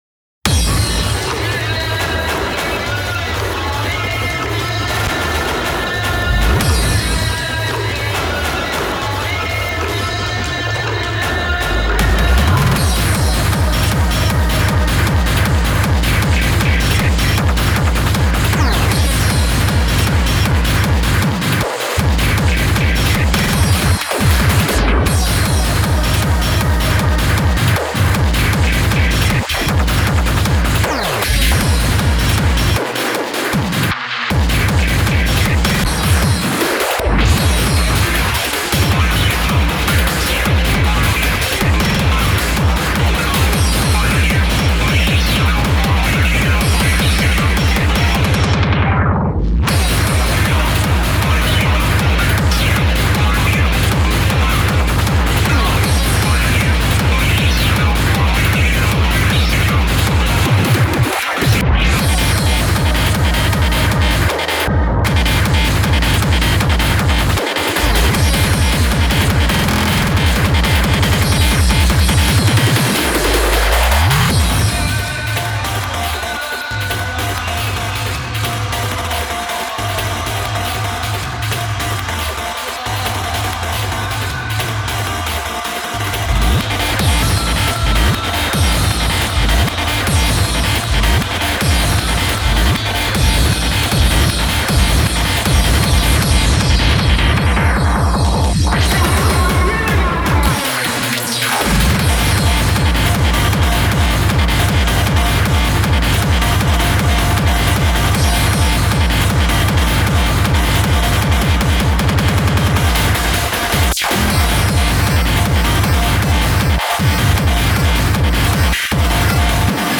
BPM156
Audio QualityPerfect (High Quality)
Genre: SCHRANZ.